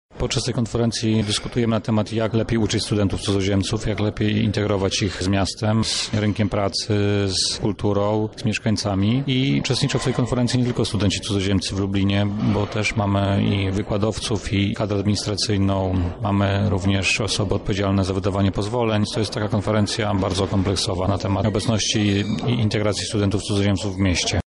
O celach konferencji mówi